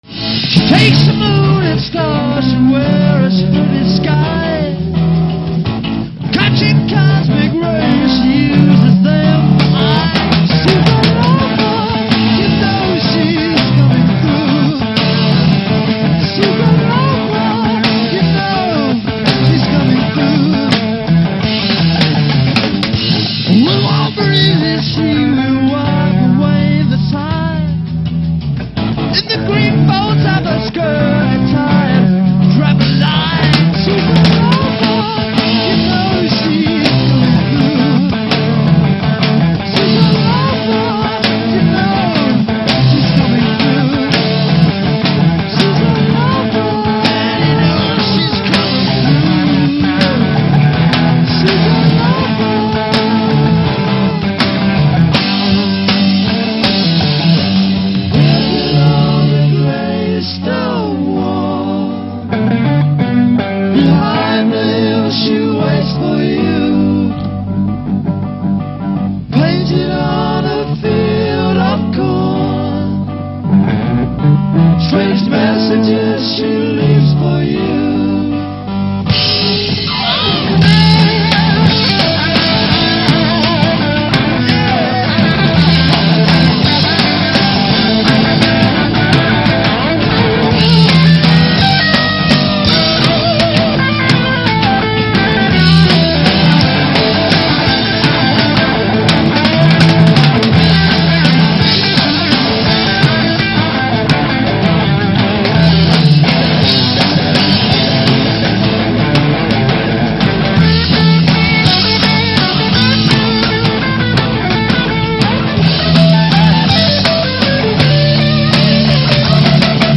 recorded in Paris
French Television.